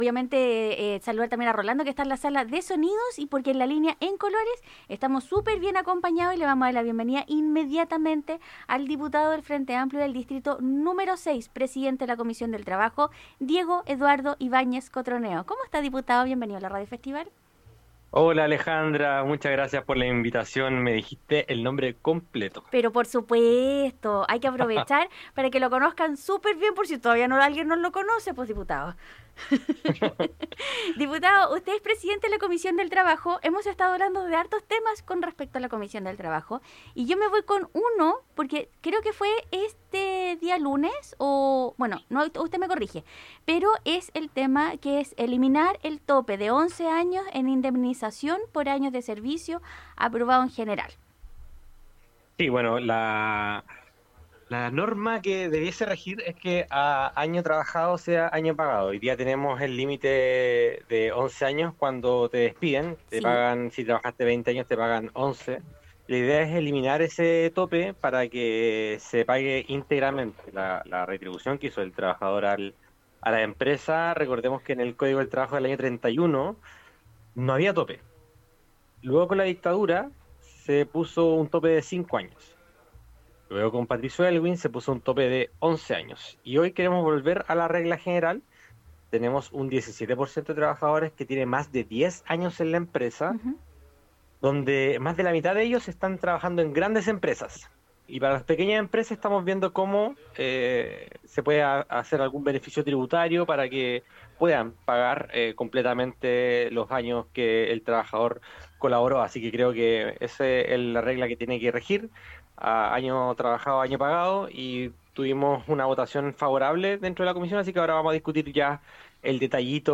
Esta mañana el Diputado del Distrito 6 comentó como va avanzando el proyecto de ley para eliminar los 11 de indemnización, así como el proyecto que otorga permisos laborales a los vocales de mesa, entre otros.